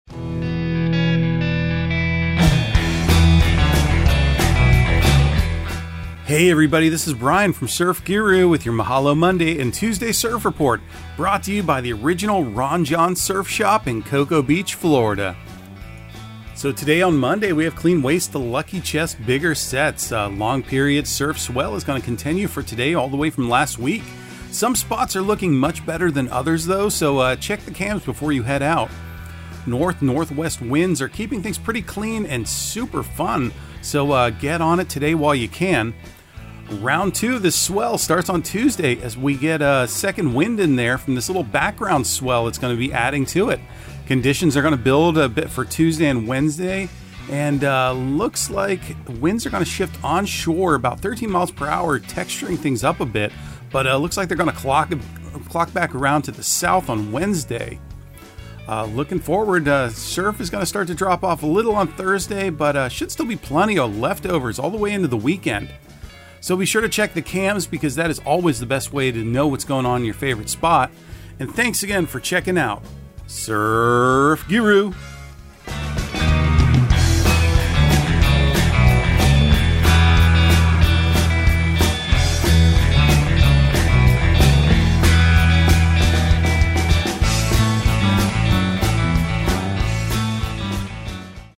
Surf Guru Surf Report and Forecast 12/12/2022 Audio surf report and surf forecast on December 12 for Central Florida and the Southeast.